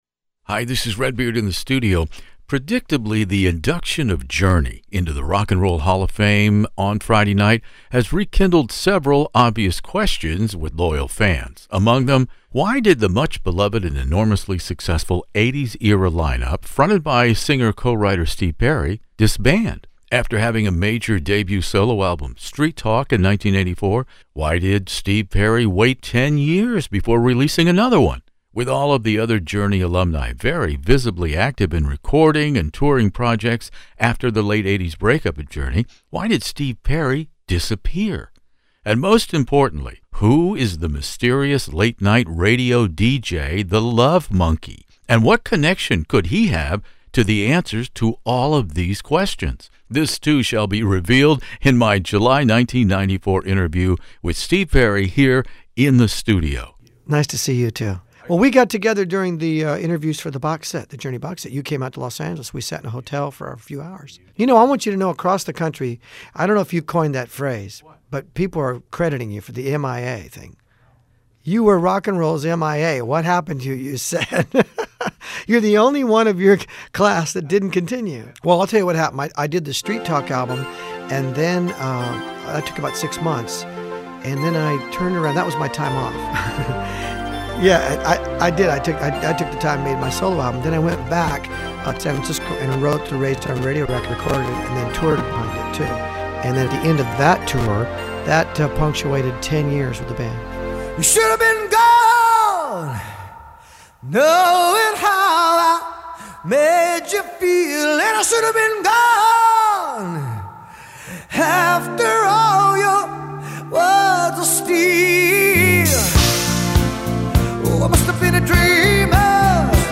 As you will hear in this July 1994 interview rewind, the end of the protracted Raised on Radio tour in the conspicuously un-California setting of Anchorage Alaska seemed symbolic on many levels to Steve Perry.